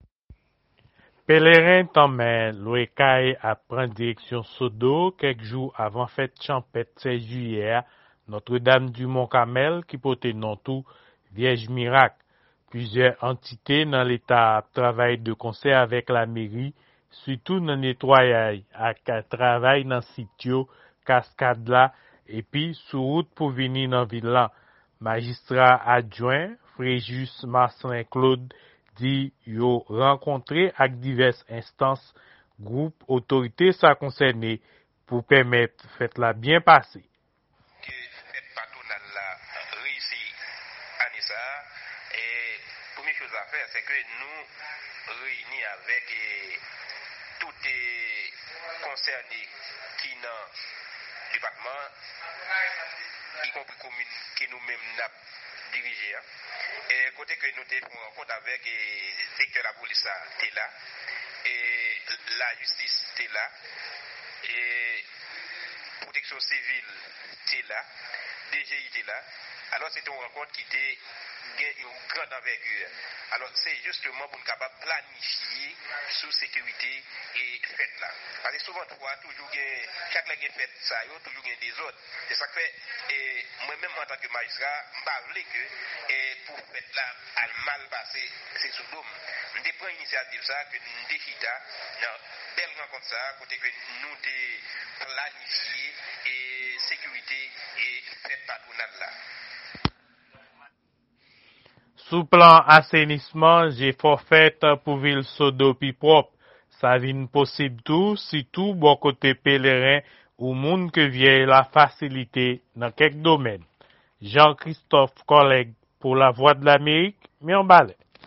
Yon repòtaj